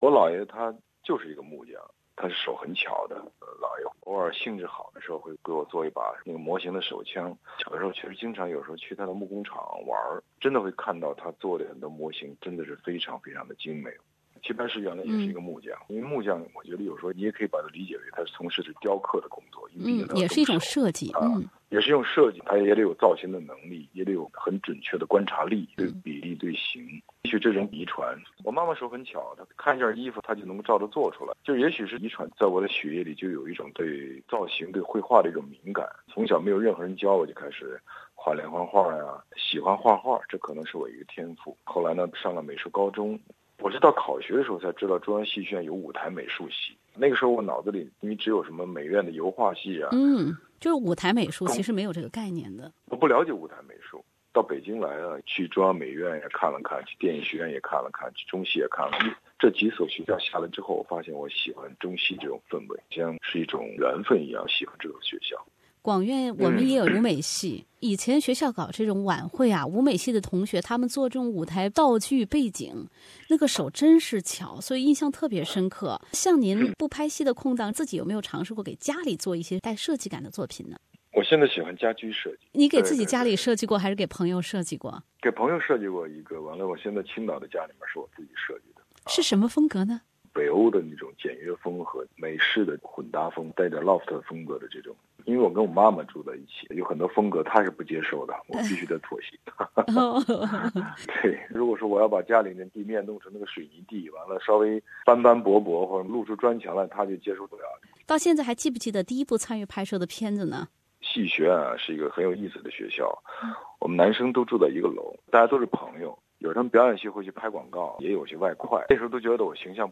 明星访谈："型男“ 谭凯